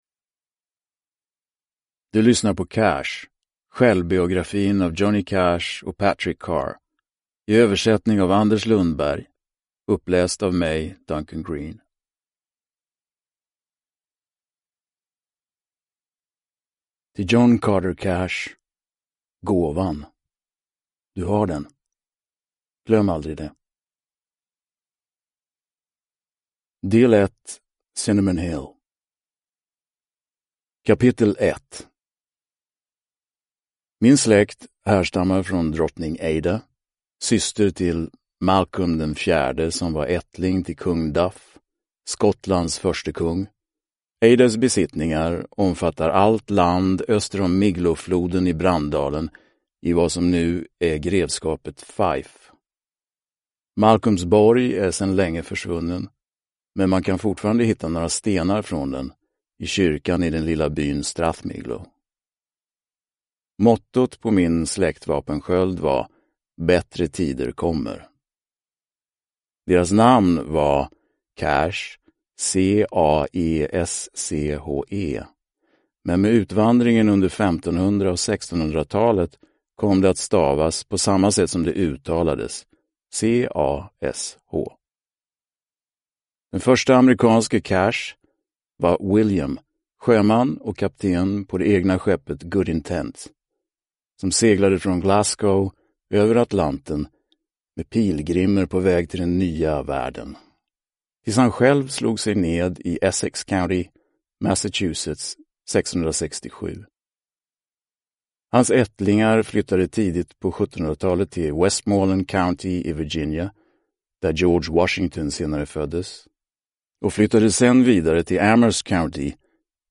Cash: Självbiografin (ljudbok) av Johnny Cash